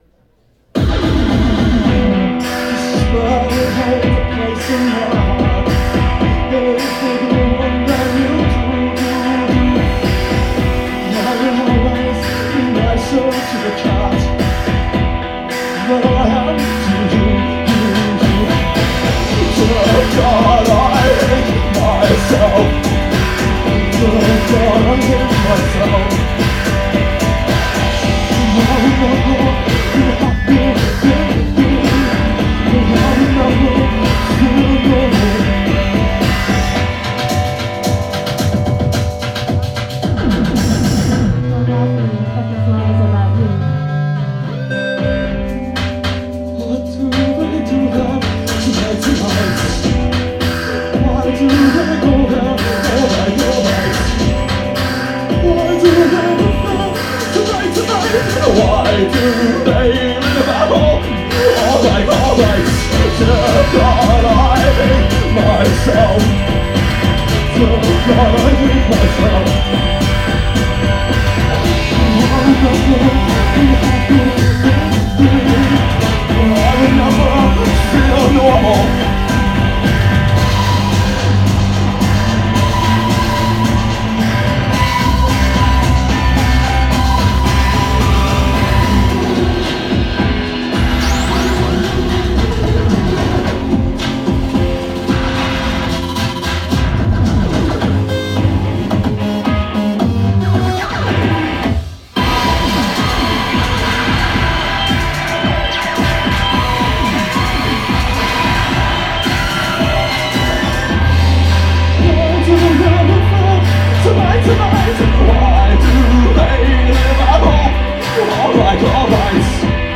This is a live take on the title track of their new album.